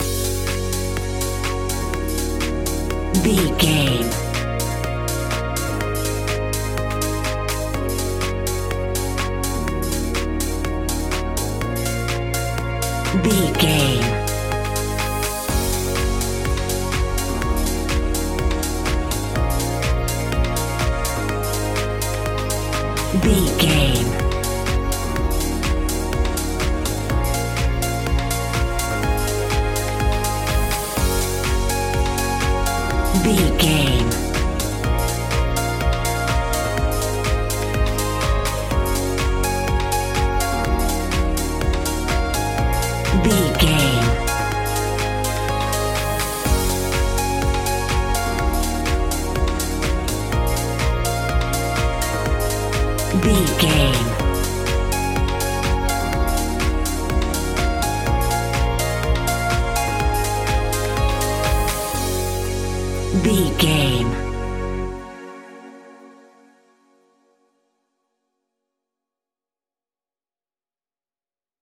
Aeolian/Minor
B♭
groovy
hypnotic
uplifting
drum machine
synthesiser
house
Funk
electro house
synth leads
synth bass